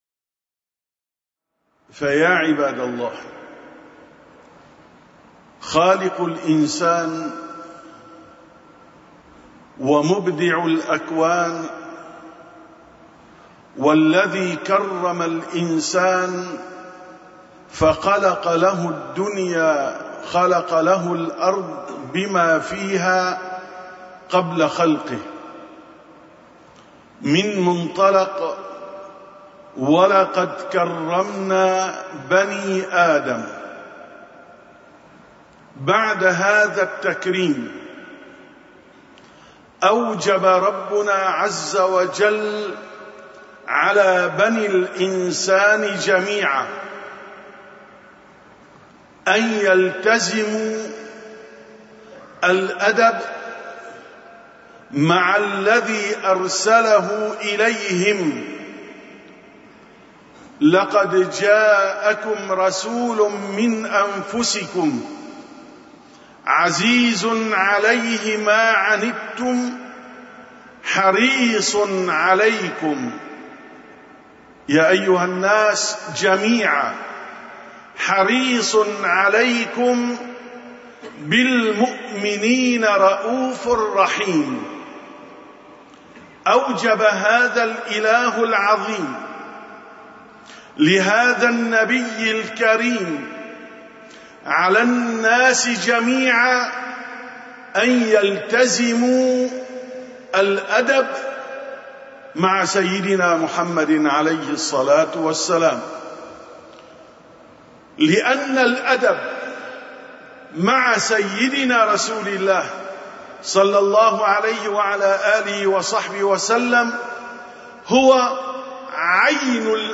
927ـ خطبة الجمعة: الأدب معه صلى الله عليه وسلم